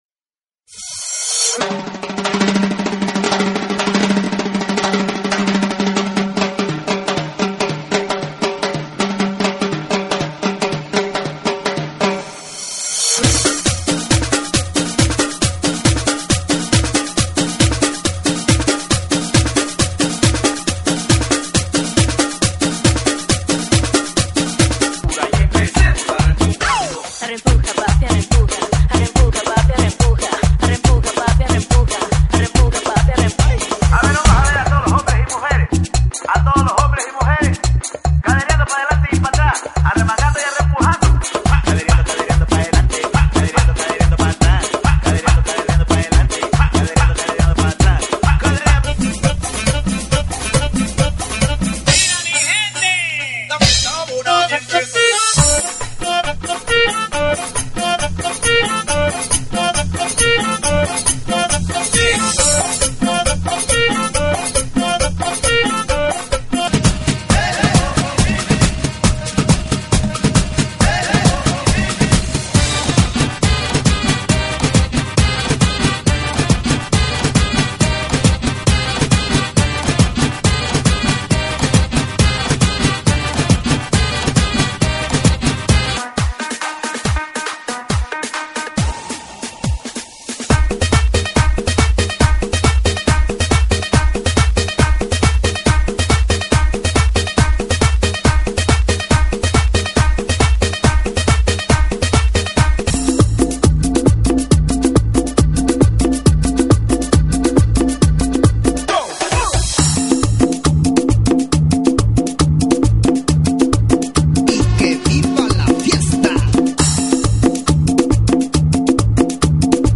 GENERO: LATINO – TRIBAL
LATINO TRIBAL,